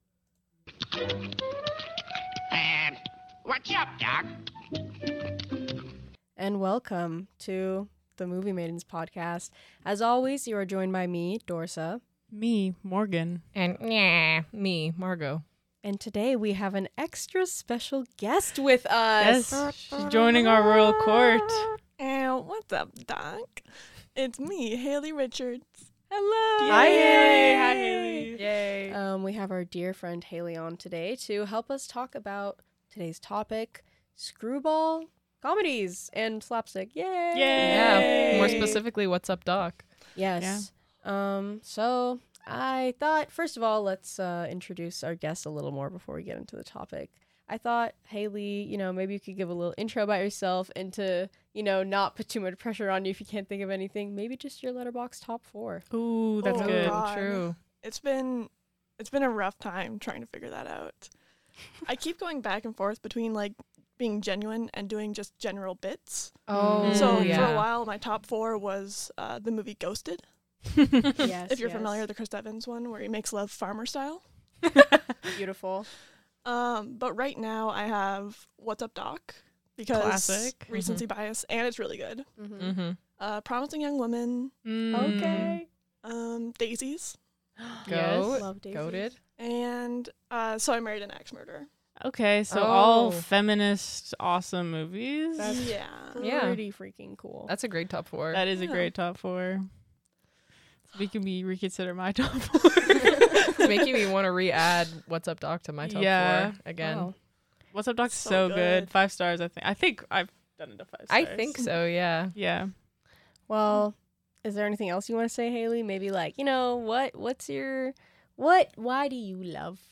Beware, unlike traditional screwballs, this episode contains foul language!